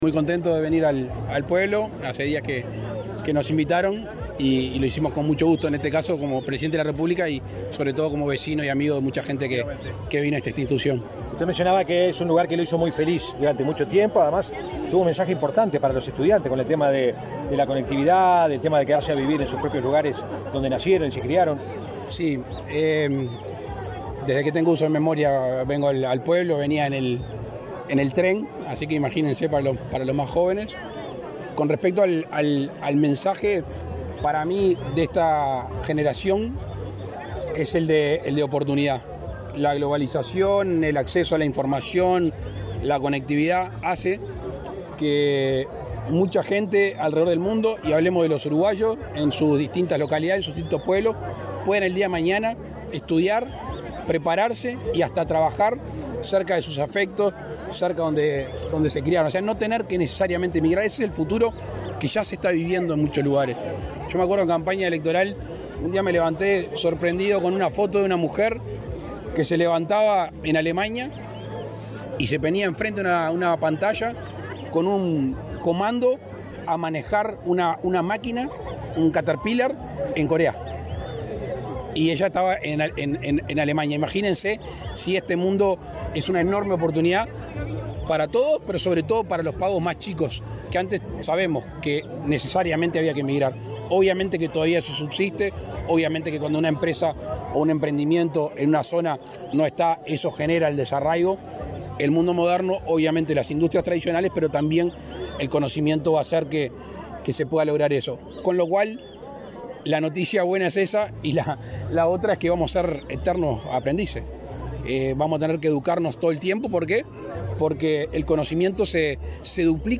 El presidente Luis Lacalle Pou dialogó con la prensa en Florida, luego de participar del festejo por el cincuentenario del liceo de Cerro Colorado.